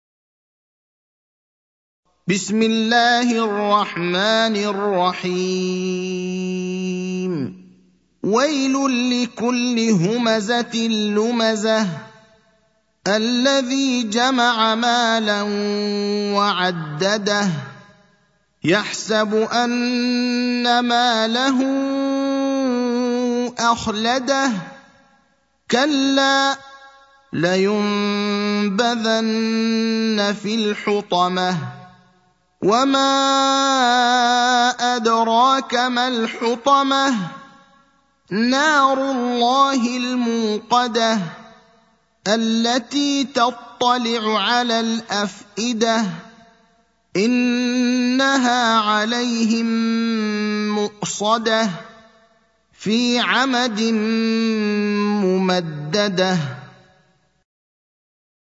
المكان: المسجد النبوي الشيخ: فضيلة الشيخ إبراهيم الأخضر فضيلة الشيخ إبراهيم الأخضر الهمزة (104)